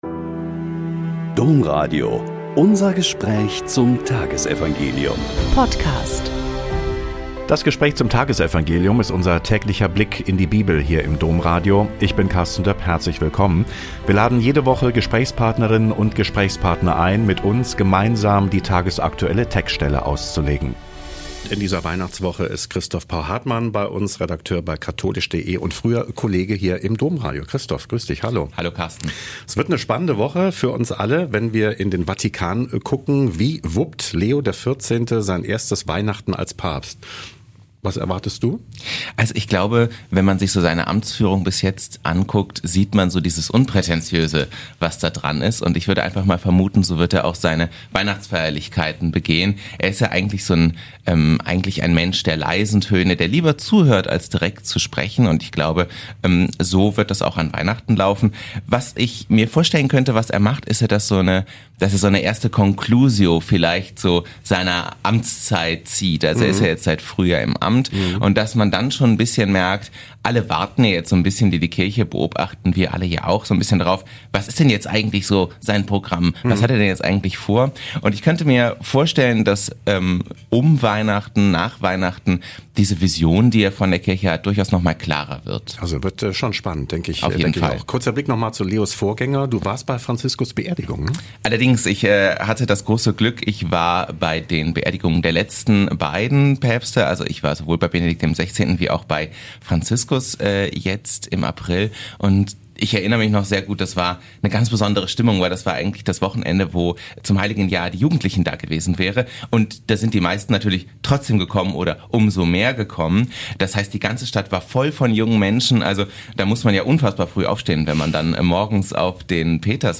Lk 1,57-66 - Gespräch